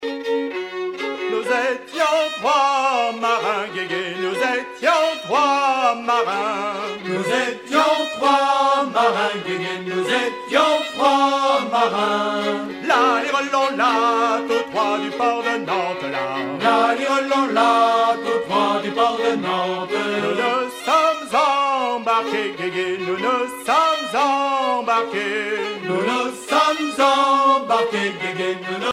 danse : ronde
circonstance : maritimes
Pièce musicale éditée